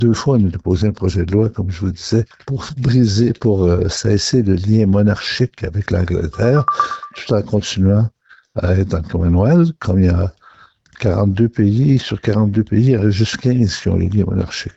Le plus ancien député de la Chambre des communes a rappelé que le Bloc québécois a déjà tenté dans le passé de mettre de côté cette monarchie.